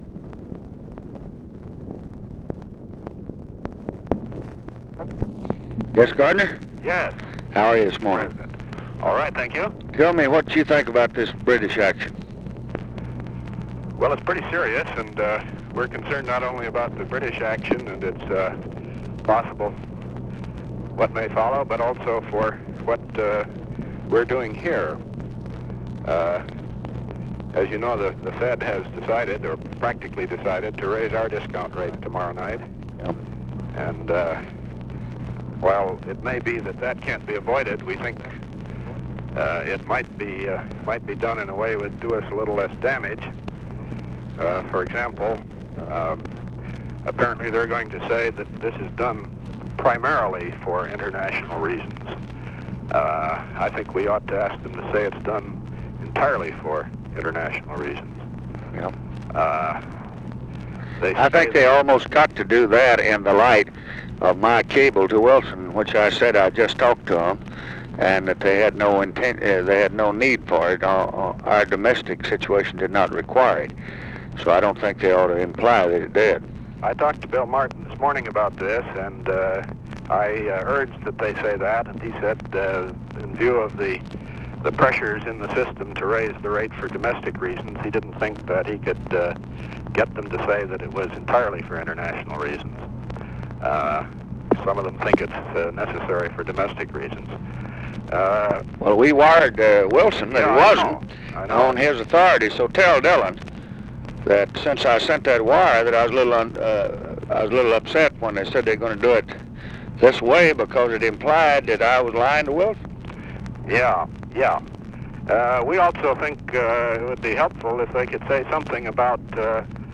Conversation with GARDNER ACKLEY, November 22, 1964
Secret White House Tapes